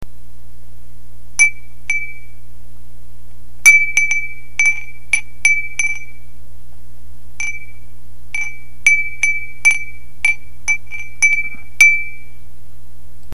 furin.mp3